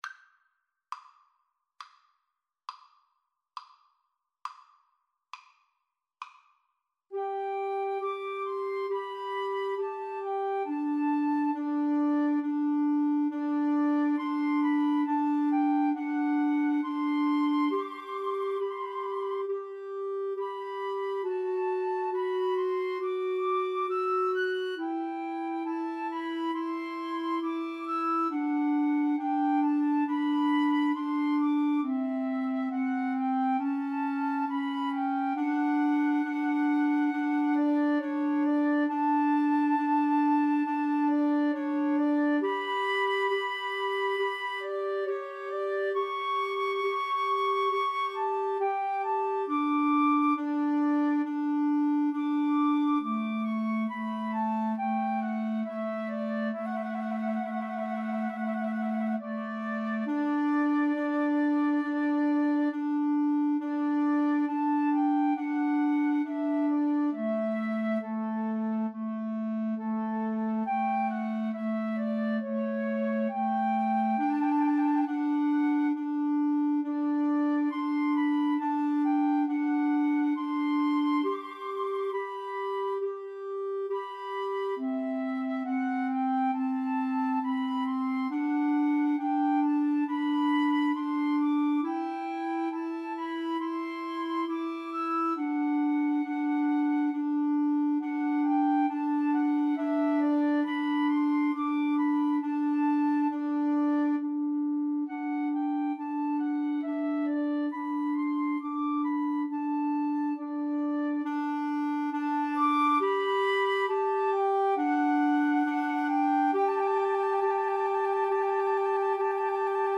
= 34 Grave
Classical (View more Classical 2-Flutes-Clarinet Music)